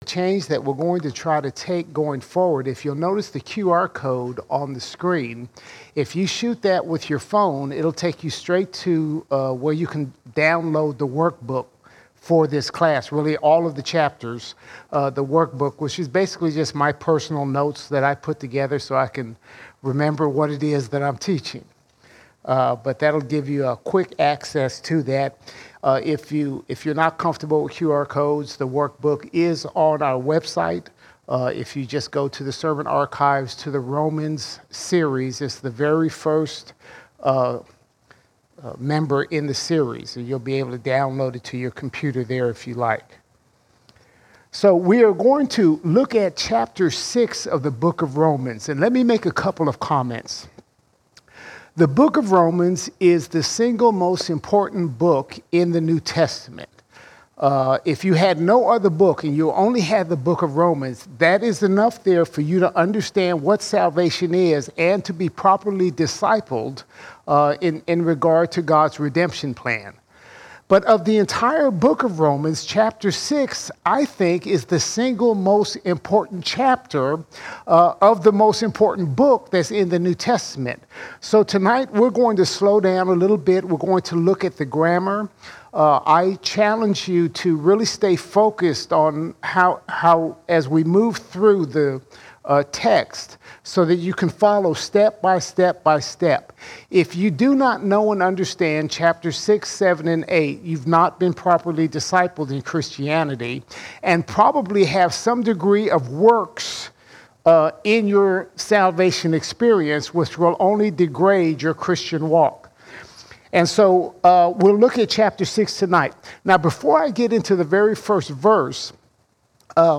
24 August 2023 Series: Romans All Sermons Roman 6:1 to 6:12 Roman 6:1 to 6:12 We learn not to sin so that grace may about.